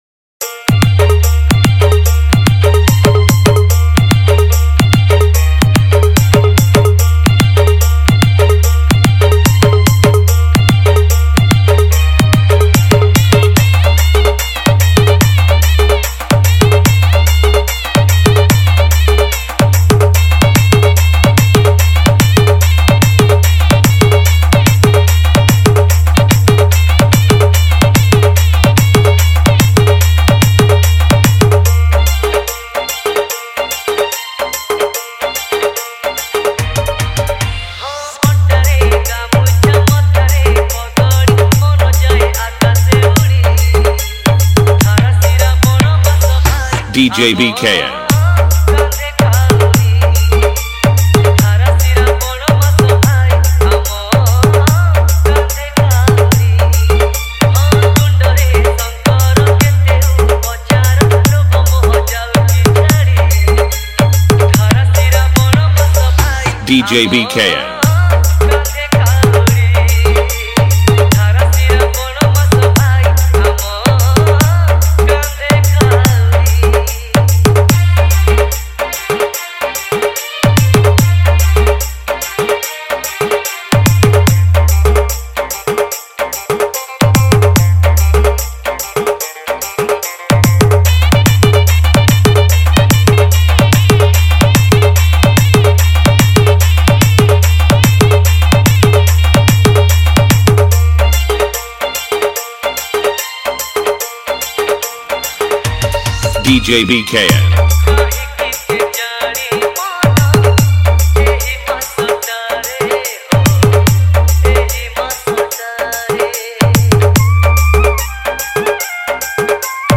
Bolbum Special Dj Song